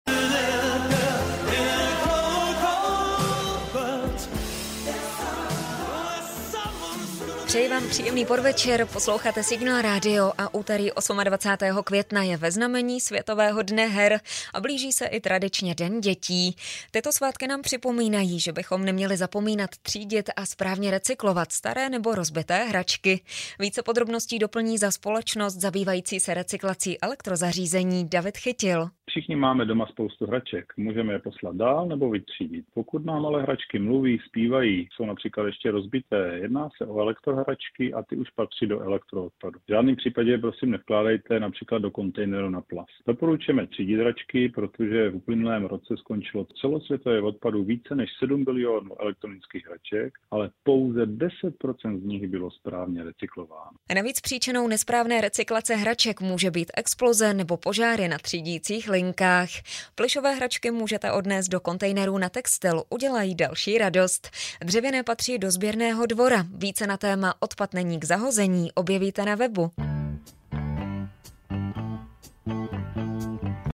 Reportaz_radio_Signal_tema_recyklace_hracek.mp3